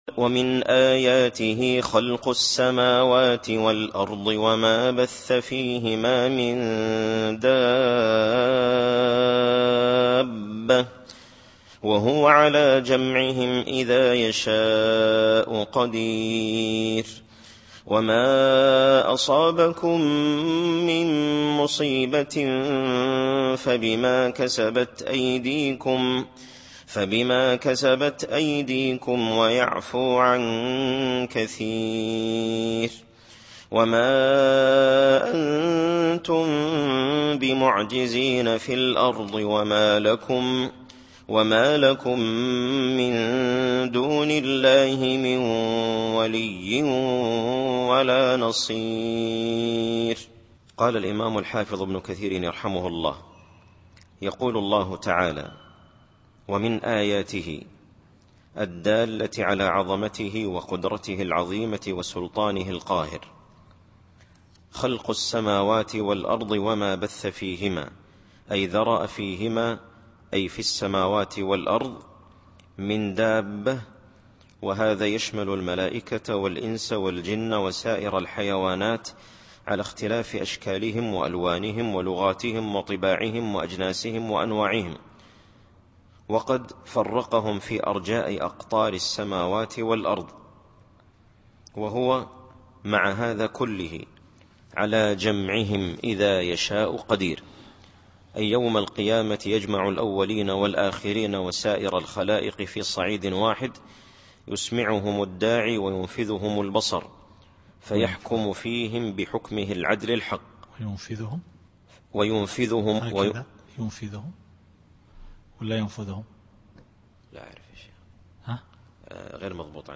التفسير الصوتي [الشورى / 29]